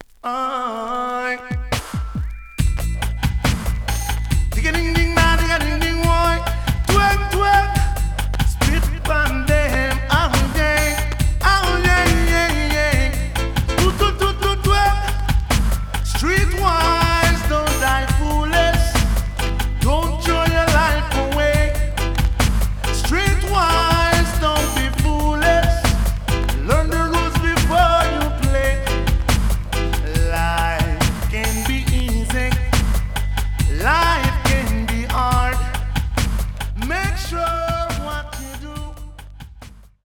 Genre: Reggae, Roots